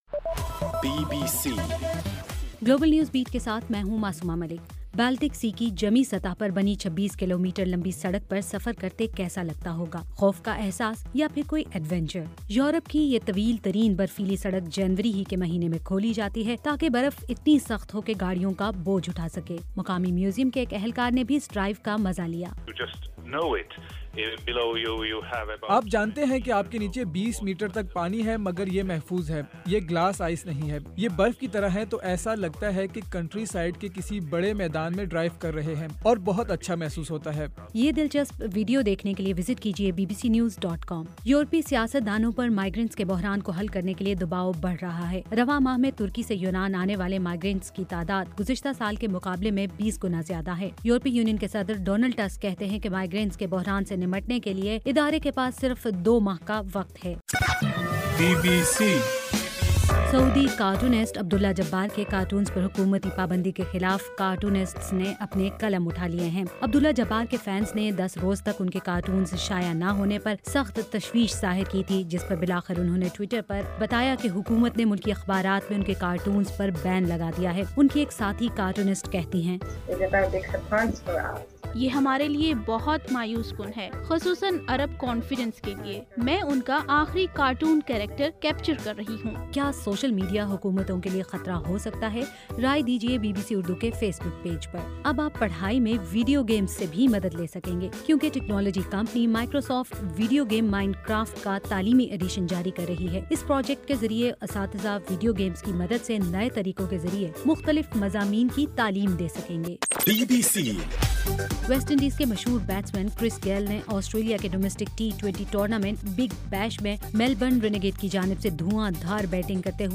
جنوری 19: رات 12 بجے کا گلوبل نیوز بیٹ بُلیٹن